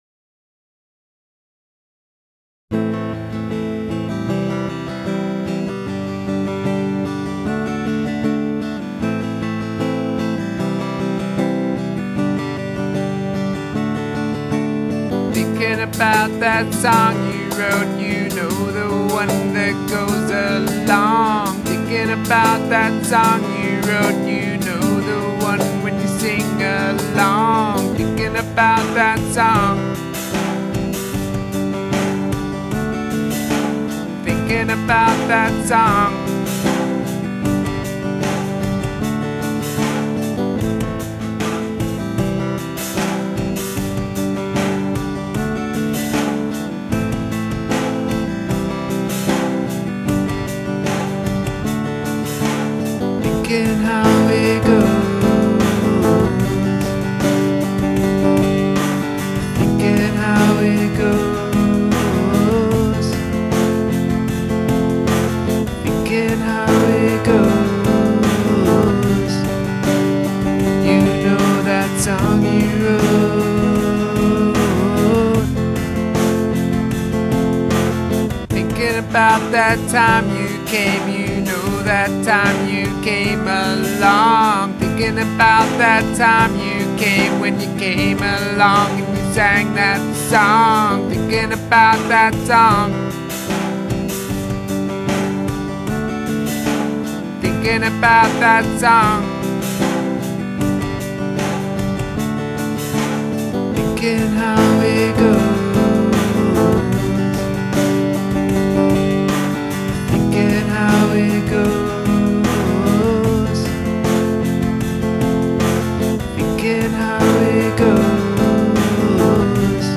Alternative Rock Duo